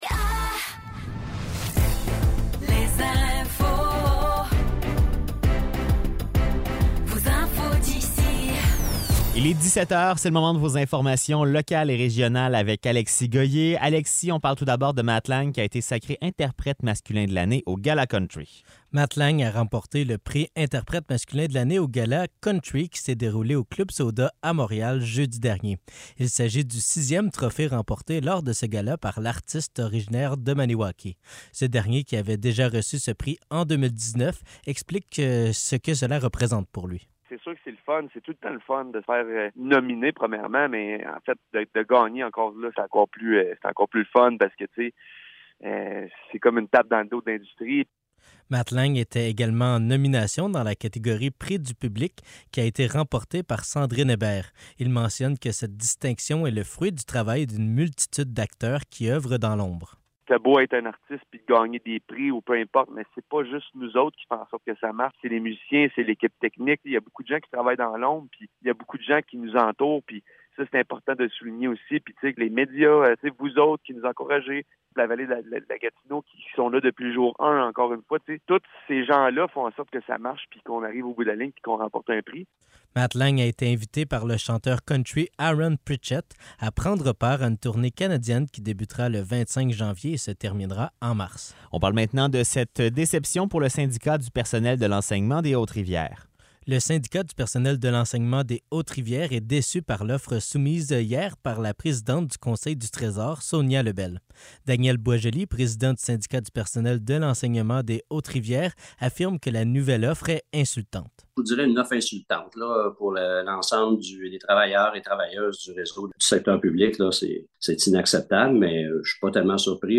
Nouvelles locales - 30 octobre 2023 - 17 h